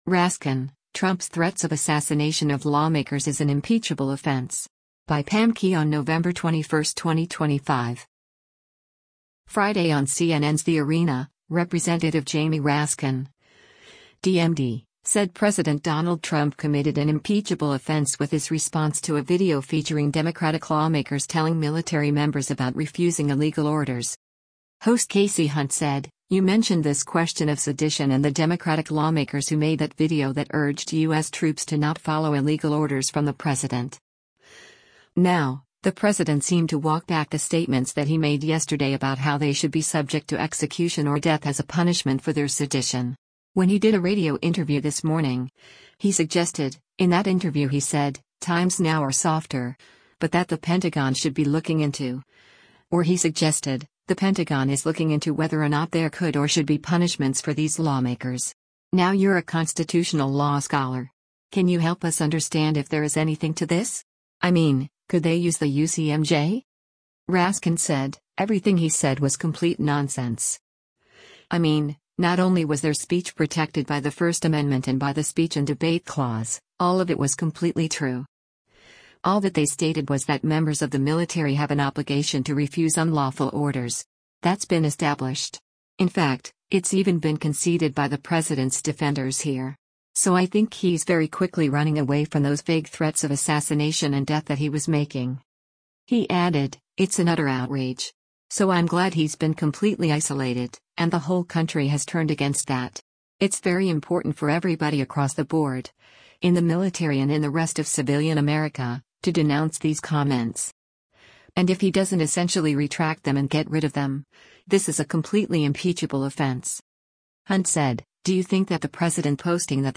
Friday on CNN’s “The Arena,” Rep. Jamie Raskin (D-MD) said President Donald Trump committed an “impeachable offense” with his response to a video featuring Democratic lawmakers telling military members about refusing illegal orders.